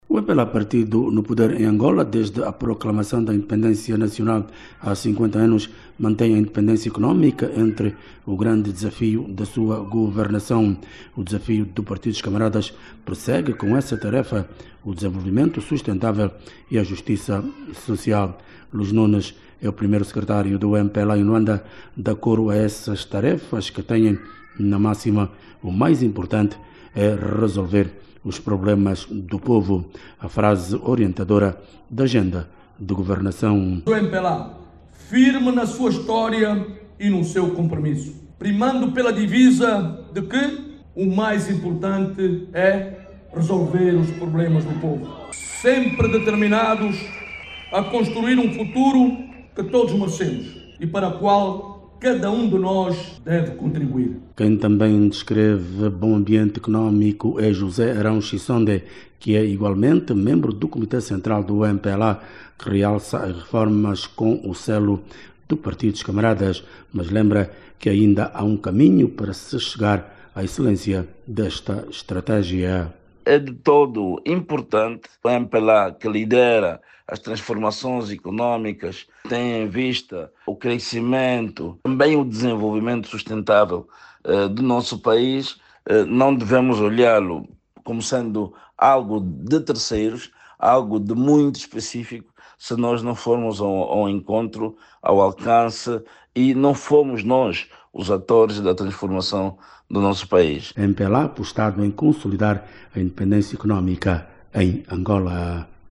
O MPLA, mantém a independência económica entre os grandes desafios da sua governação. Apesar dos persistentes constrangimentos da economia mundial, o partido no poder continua empenhado em trabalhar para o bem-estar da população. Ouça no áudio abaixo toda informação com a reportagem